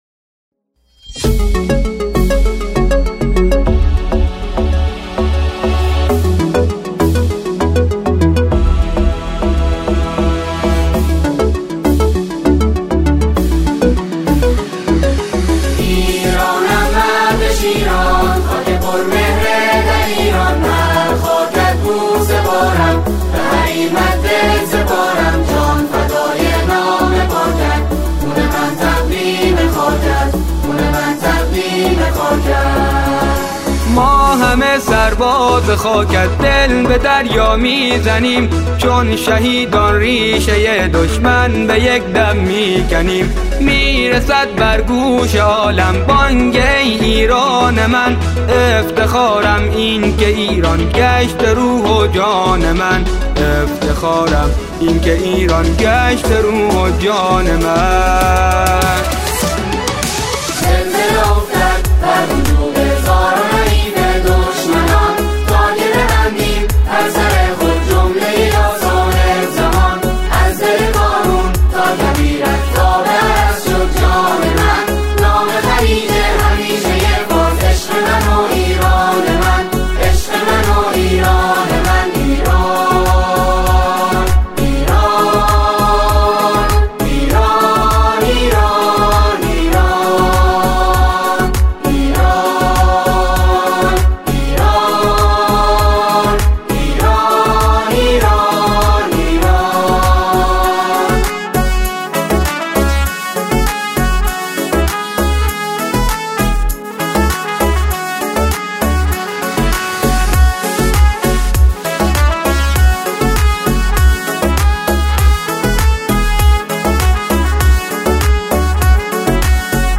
با همخوانی سه گروه بوشهری